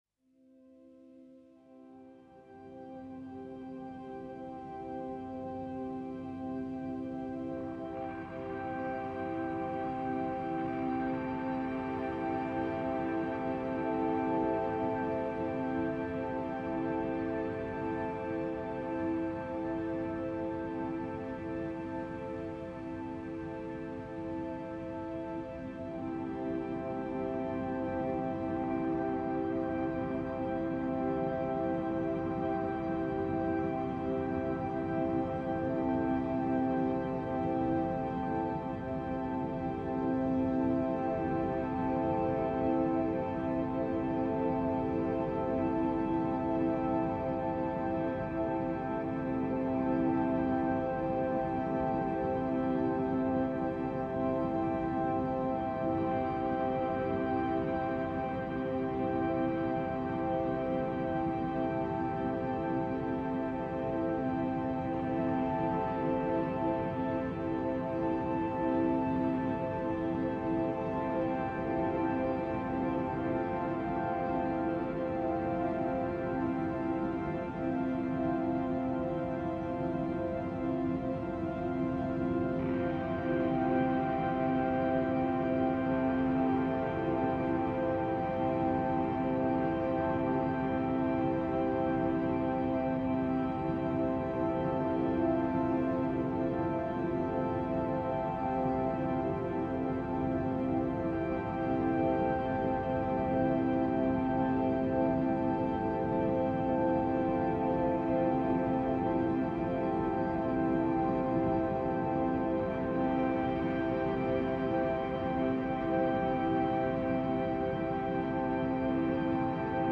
Bright Drone Version